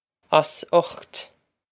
Pronunciation for how to say
Oss ukht
This is an approximate phonetic pronunciation of the phrase.